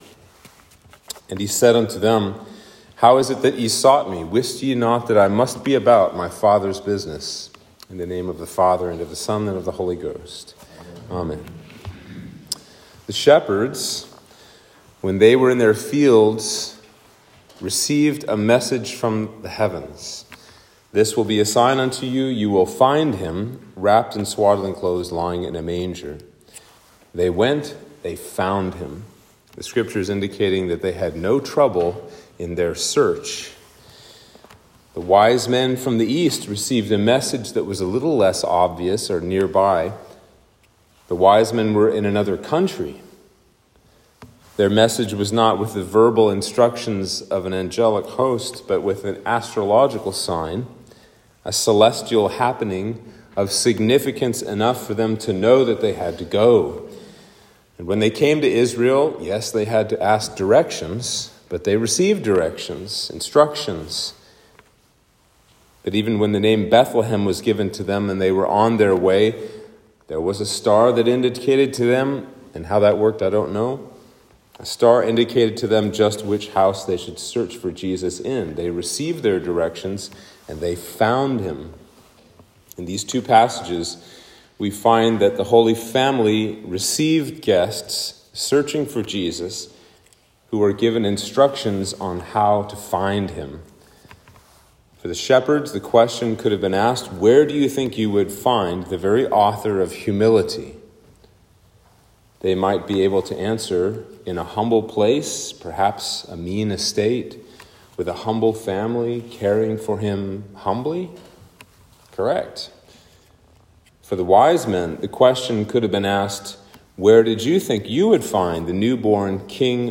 Sermon for Epiphany 1